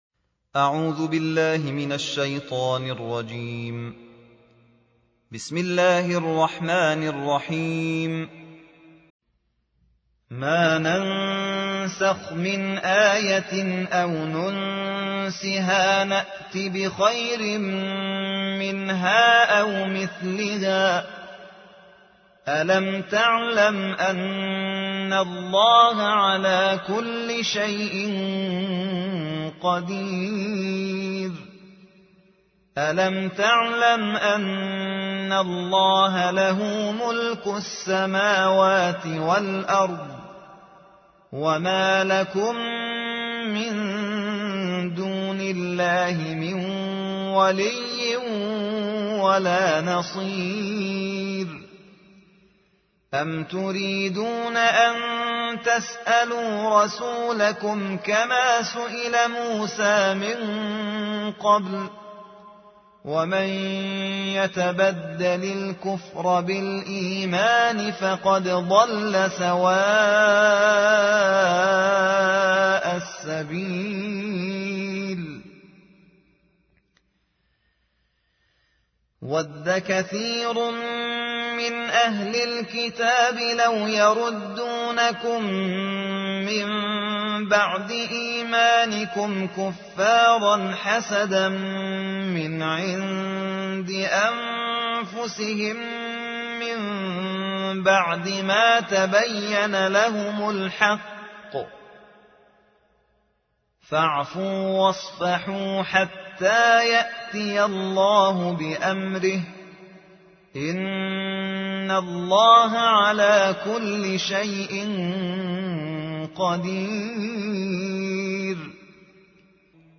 قرائت شبانه